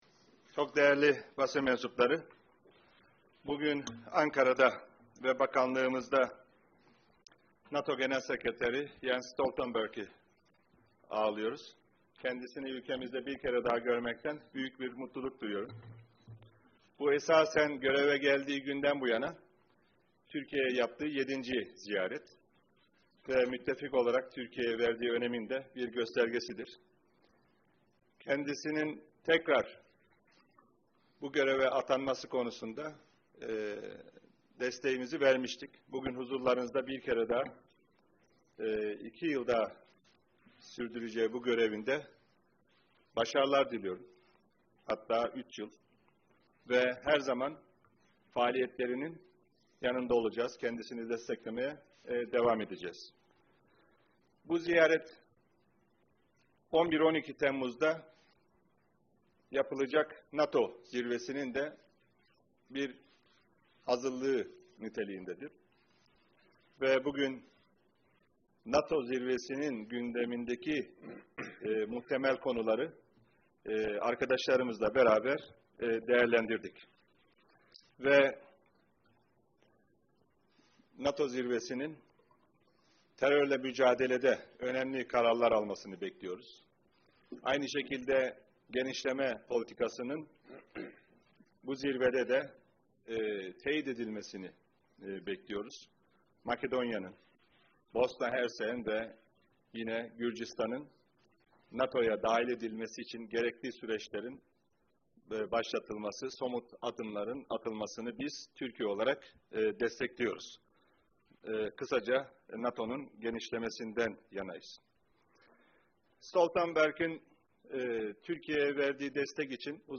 Joint press conference
with NATO Secretary General Jens Stoltenberg and the Minister of Foreign Affairs of Turkey, Mevlüt Çavuşoğlu